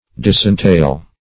Disentail \Dis`en*tail"\